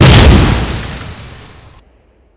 GrenExpl08.wav